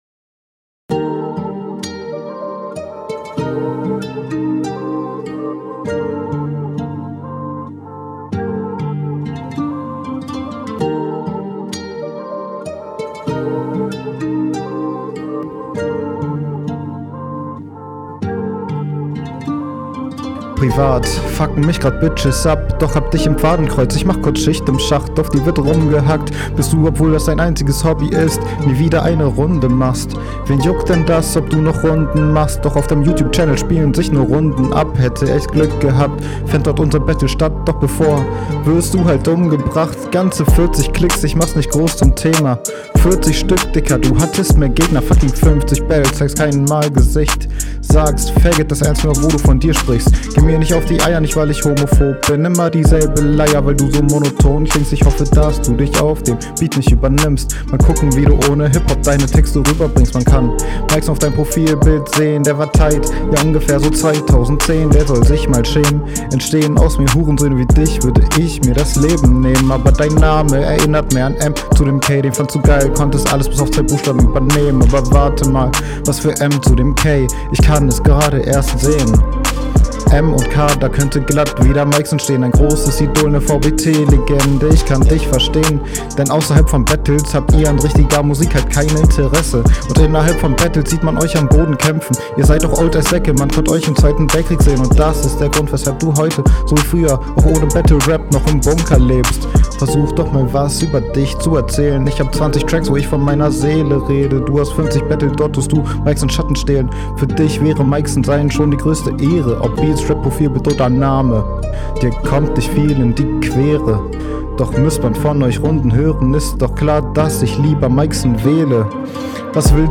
Du übersteuerst bei beiden Runden ganz minim oder bist sehr nah am Mic, stört den …
Flow: Der Beat (der übrigens sehr geil ist) steht dir auf jeden Fall besser! Wie …
Du bist off Beat mehrmals, zu dünn abgemischt und du übersteuerst häufiger